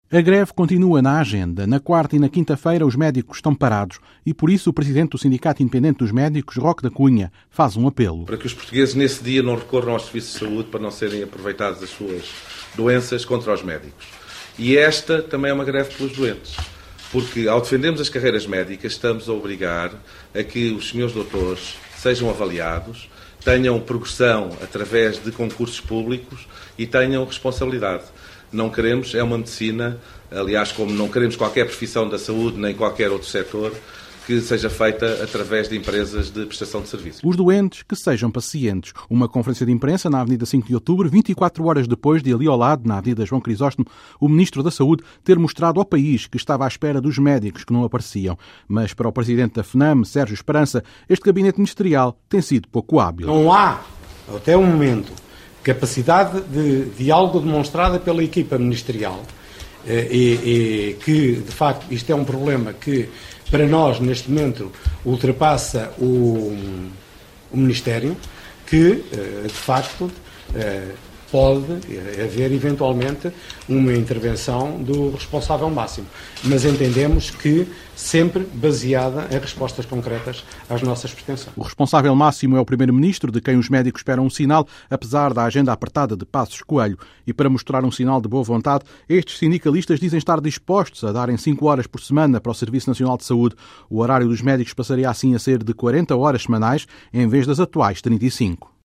Conferência de imprensa da FNAM e do SIM